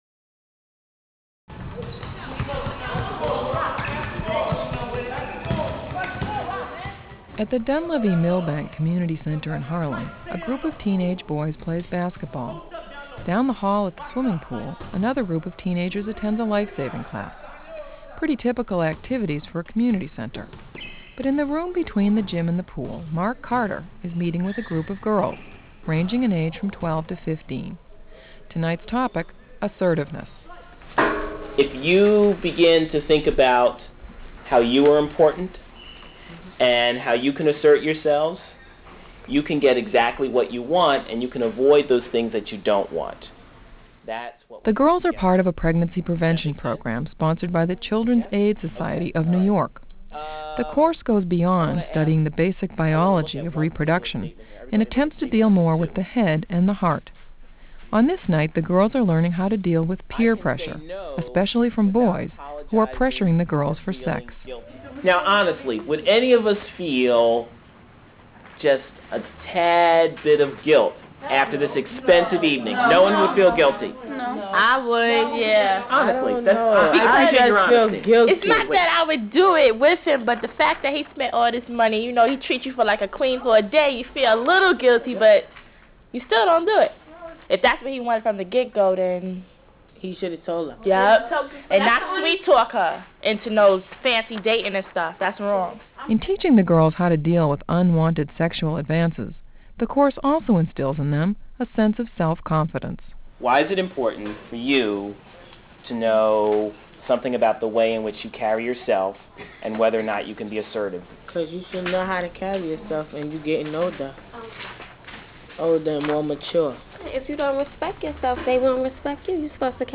This report was produced by Monitor Radio and originally broadcast in May of 1993.